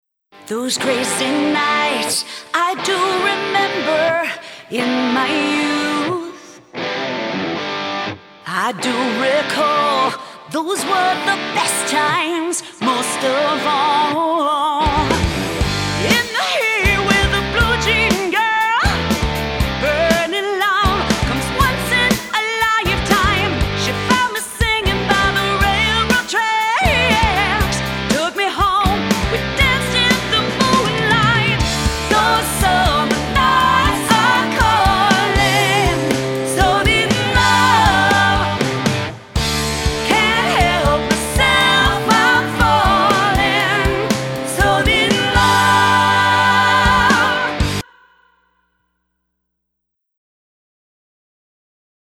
I think the vocal timing got out of sync in my mix.